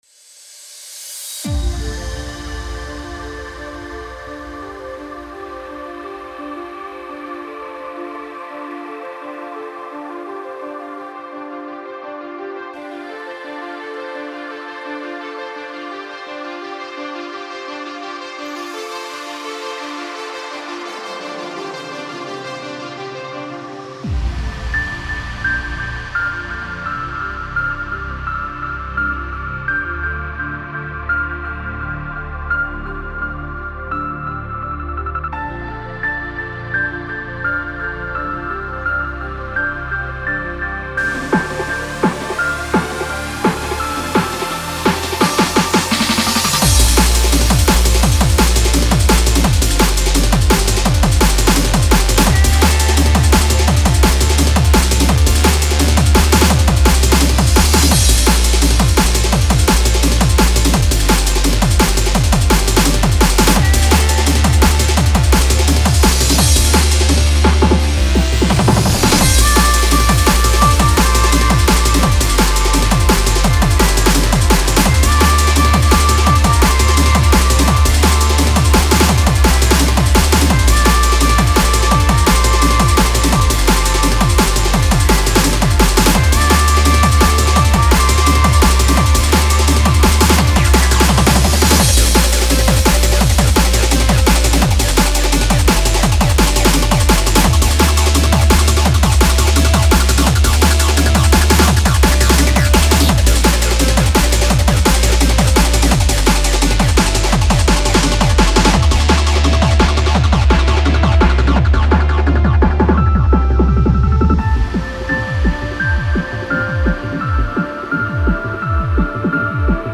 [Drum'n'bass]
Сделал простенький мелодичный трек под настроение.